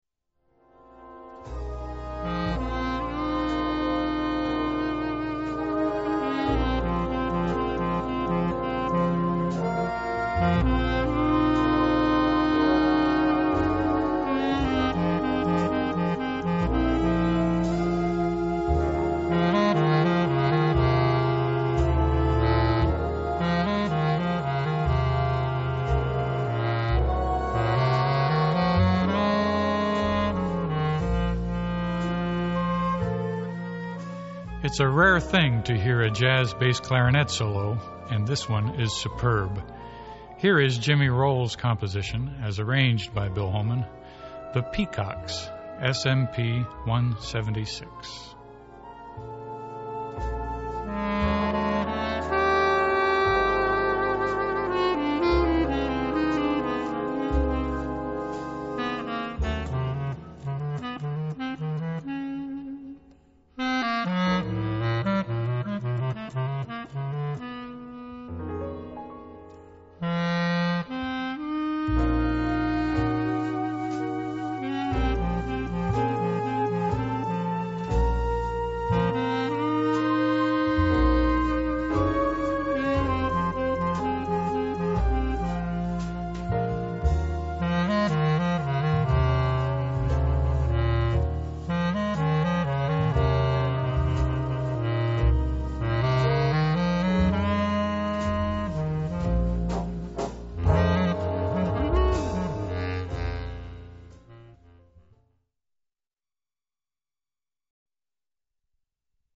Flute and soprano sax doubles.